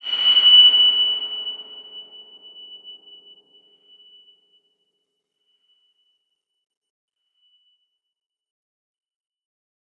X_BasicBells-F#5-pp.wav